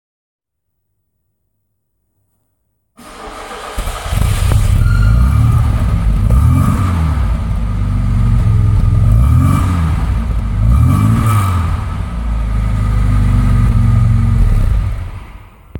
Motor: 4Zylinder Reihenmotor Sauger